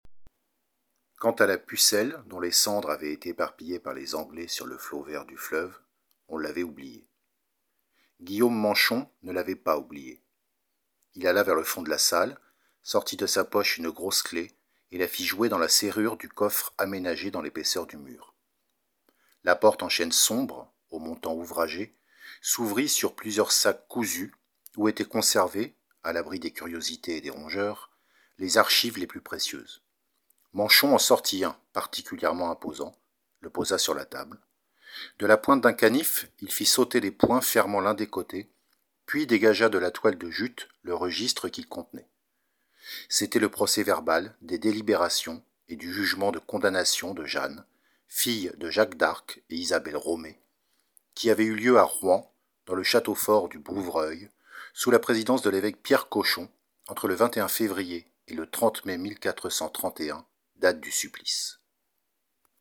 Entretien avec Michel Bernard